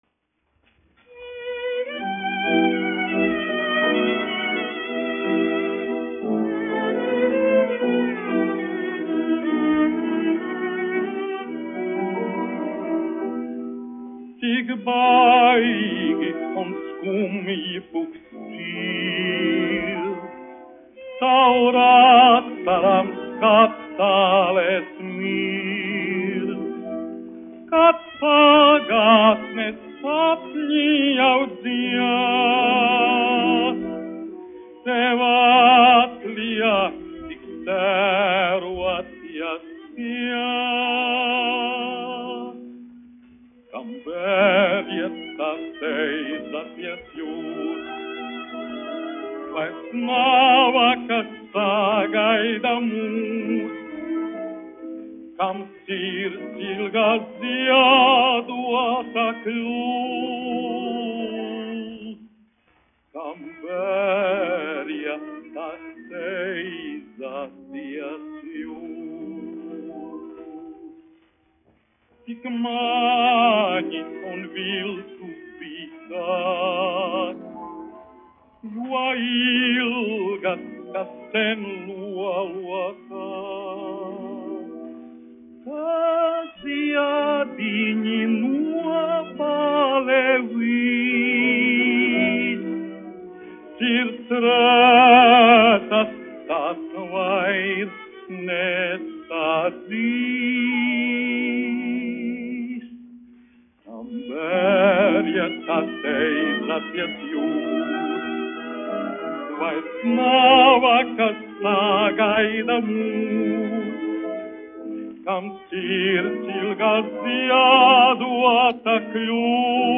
1 skpl. : analogs, 78 apgr/min, mono ; 25 cm
Dziesmas (augsta balss) ar instrumentālu ansambli
Romances (mūzika)
Latvijas vēsturiskie šellaka skaņuplašu ieraksti (Kolekcija)